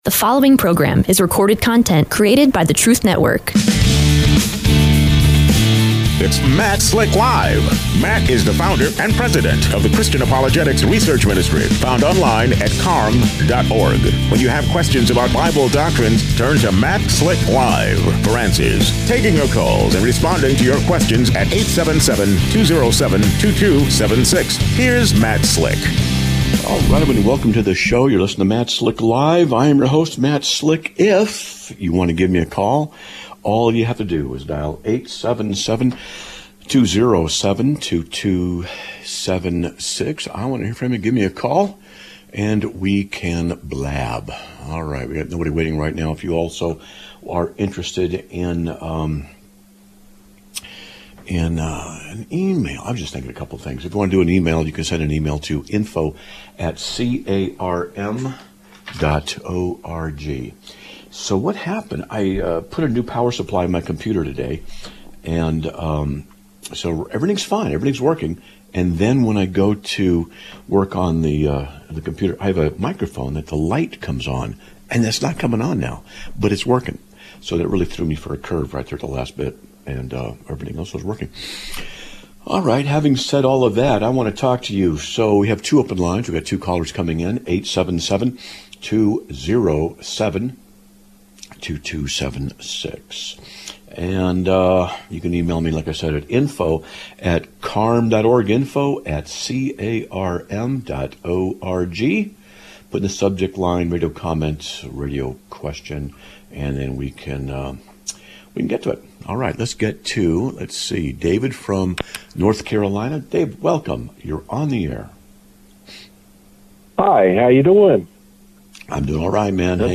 Live Broadcast of 10/15/2025